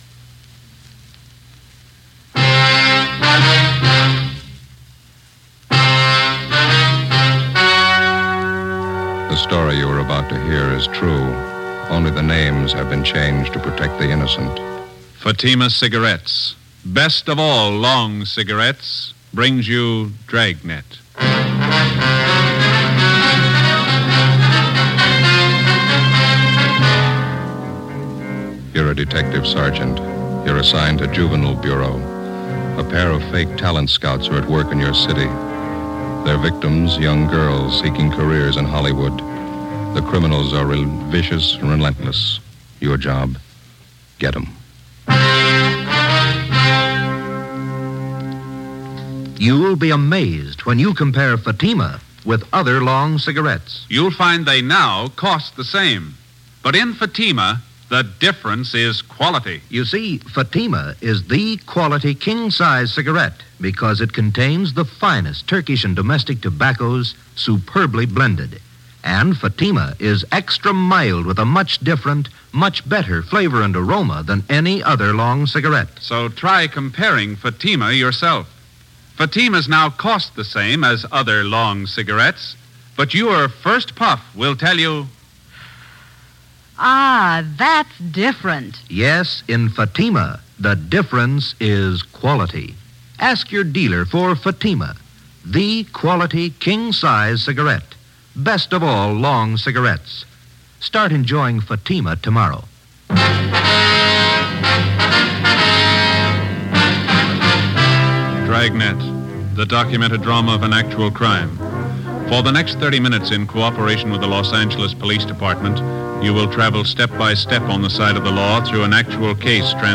Dragnet is perhaps the most famous and influential police procedural drama in media history. The series gave audience members a feel for the danger and heroism of police work.